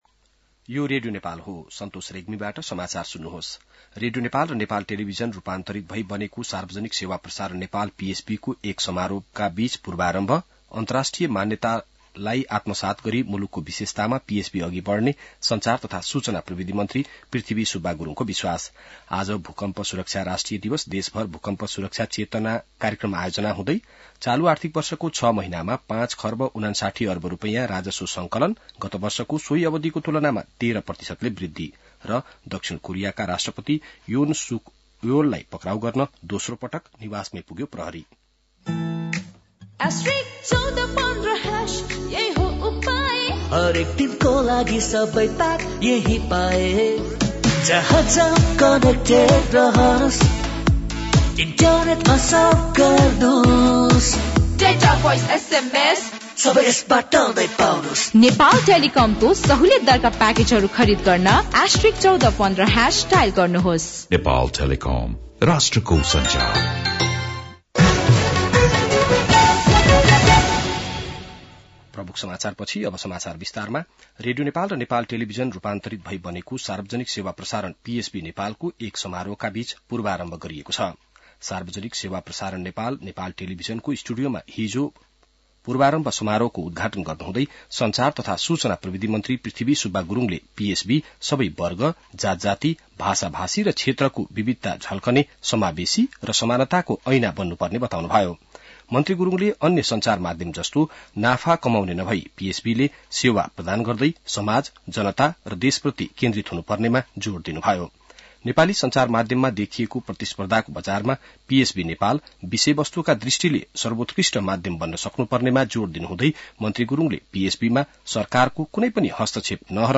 बिहान ७ बजेको नेपाली समाचार : ३ माघ , २०८१